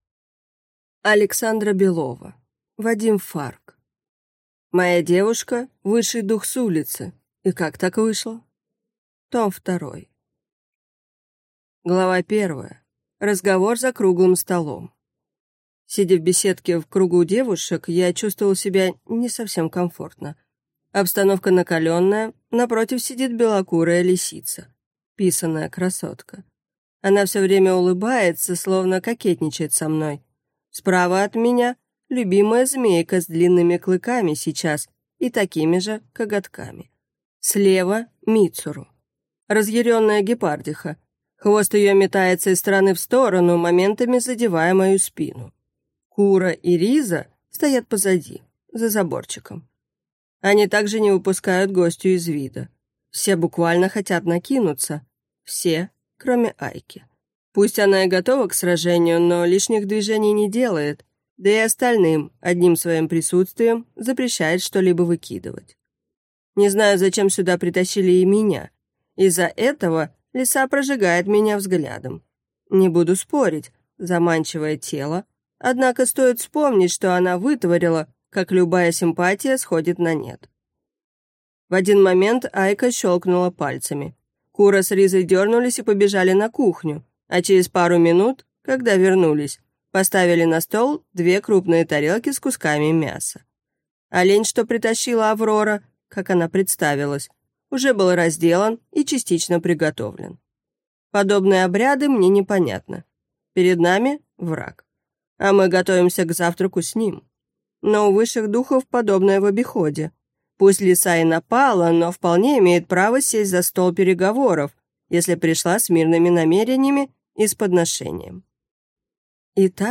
Моя девушка – высший дух с улицы. И как так вышло?! Том 2 (слушать аудиокнигу бесплатно) - автор Вадим Фарг